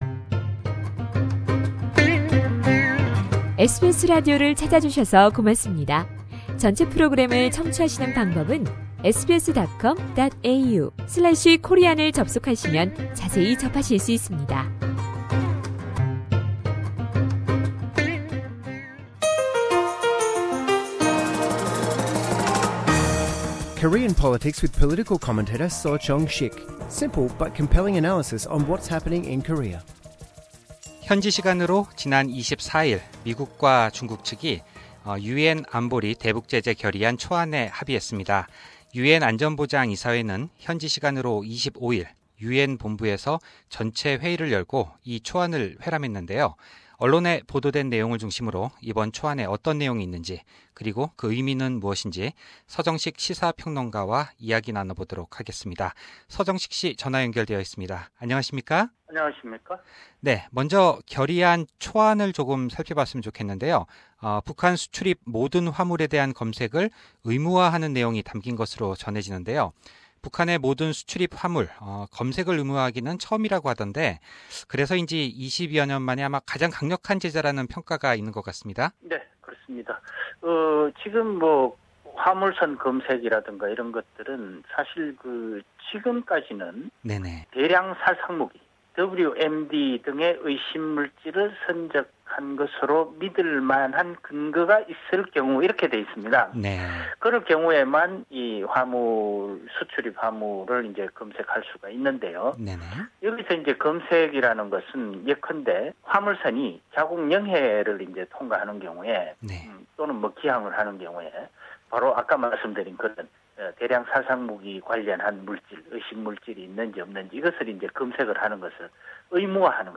We will discuss further with Korean political commentator